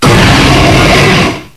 cry_not_kyurem_black.aif